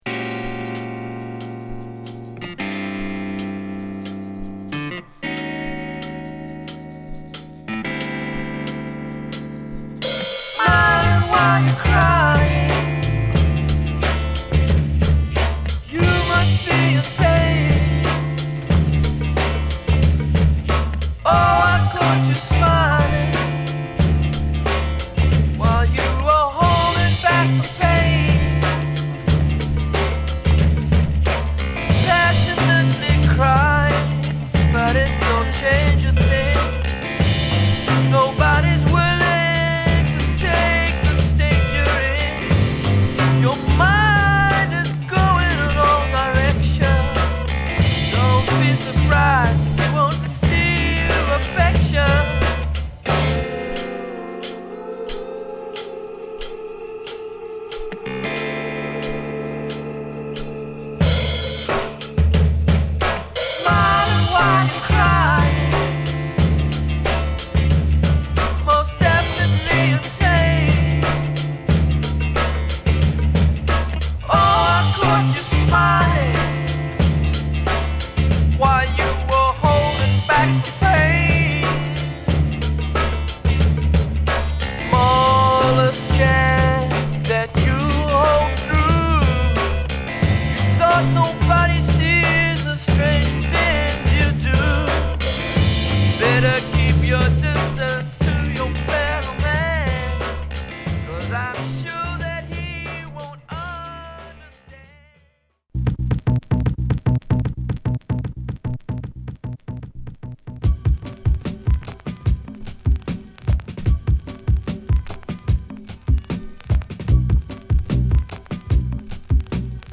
ドイツのファンクバンド